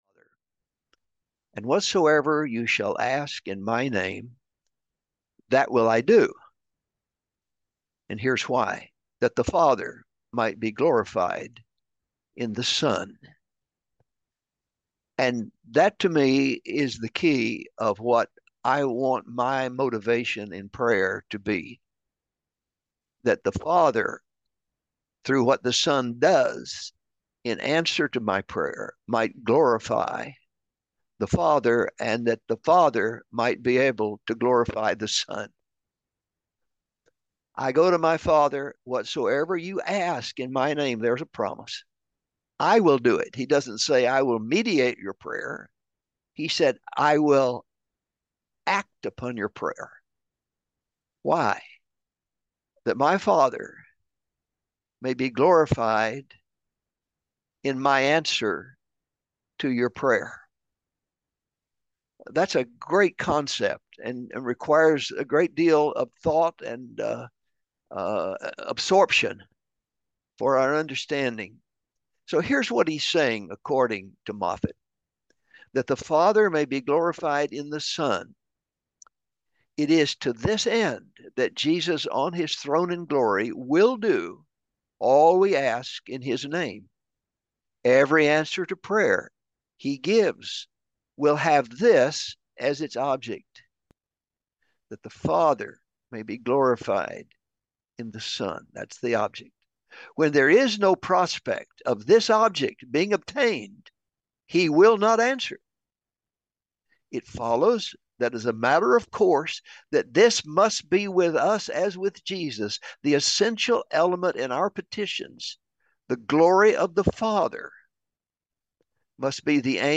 The Testing of Your Faith | SermonAudio Broadcaster is Live View the Live Stream Share this sermon Disabled by adblocker Copy URL Copied!